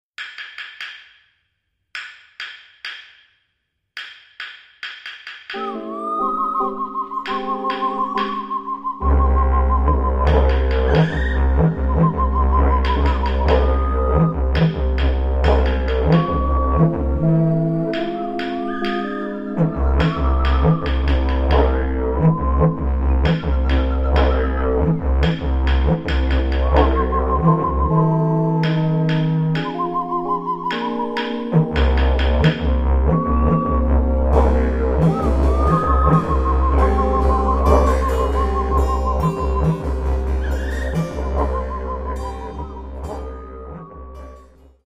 Australian classical music